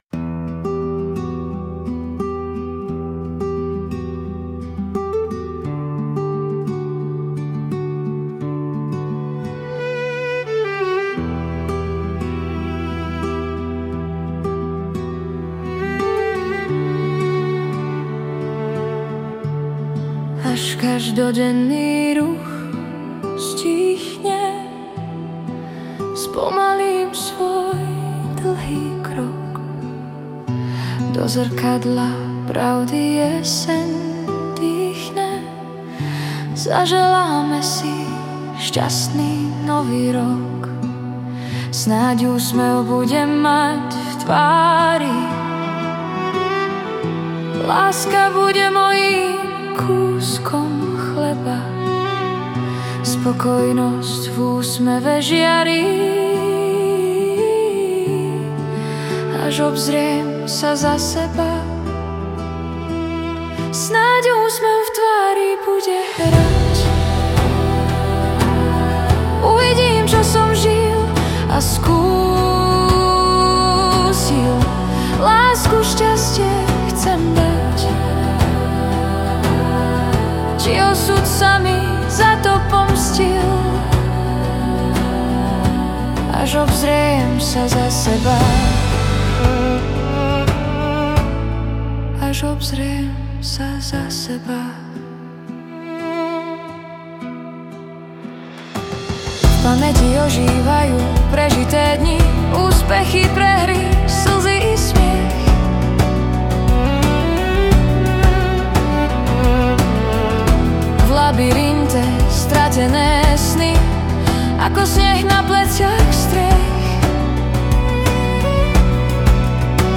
Hudba a spev AI
Balady, romance » Romantické